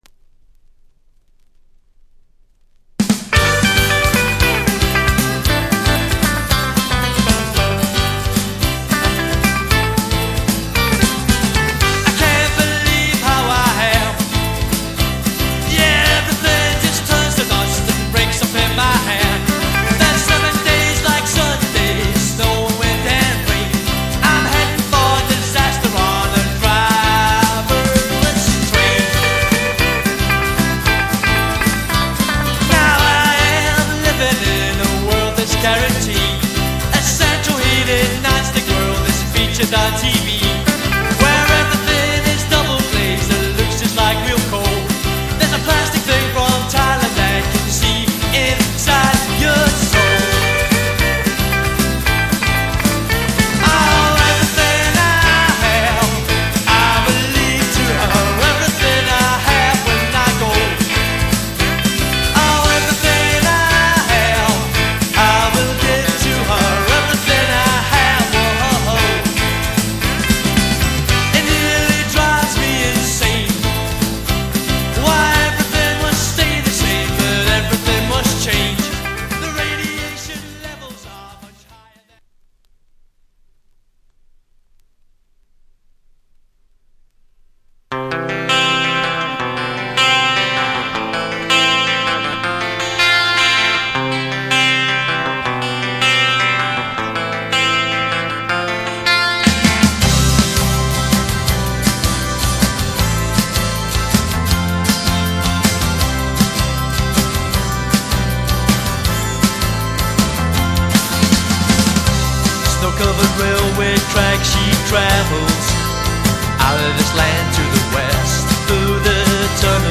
淡いアコギの響きとキーボードのアレンジがシャレた雰囲気を醸したメロウな